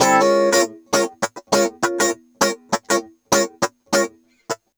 100FUNKY05-L.wav